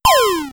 Drop_Fall.wav